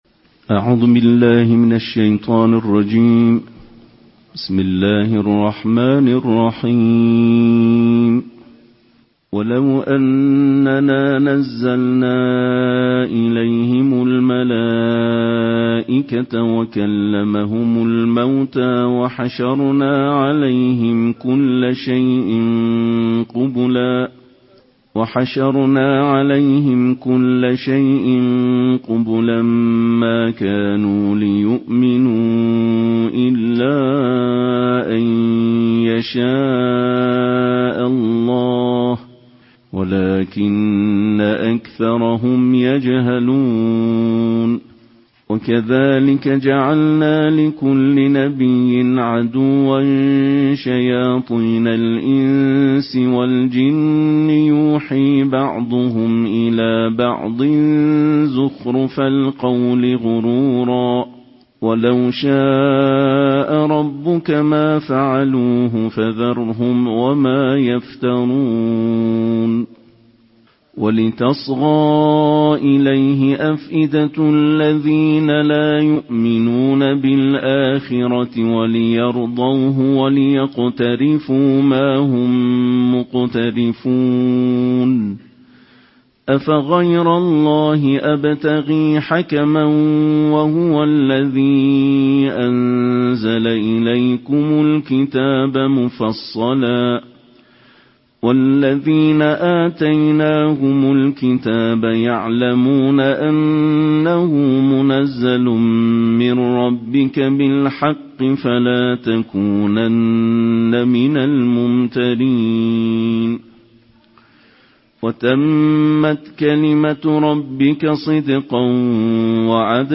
Daily Quran Recitation: Tarteel of Juz 8